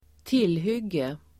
Ladda ner uttalet
Folkets service: tillhygge tillhygge substantiv, weapon Uttal: [²t'il:hyg:e] Böjningar: tillhygget, tillhyggen, tillhyggena Synonymer: vapen Definition: tillfälligt vapen weapon substantiv, vapen , tillhygge , stridsmedel